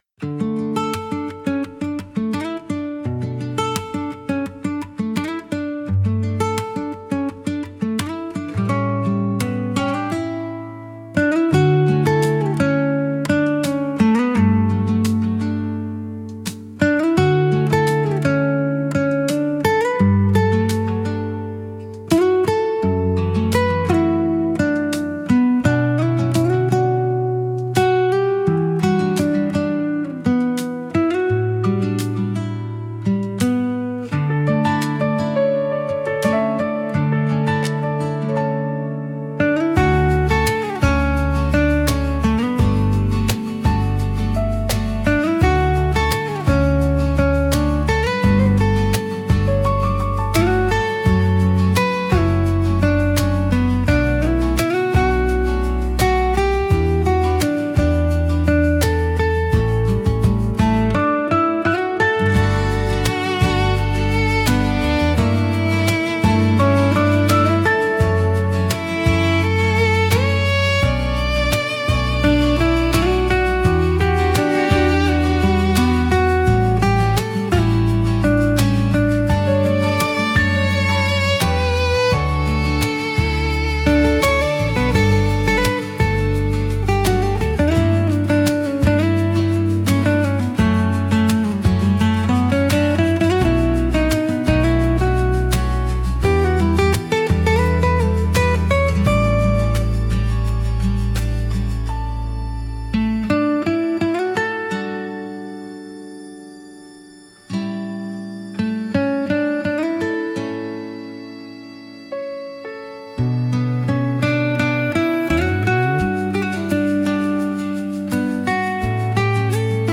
ナチュラルで優しい響きが心地よく、シンプルながら情感豊かなメロディを奏でます。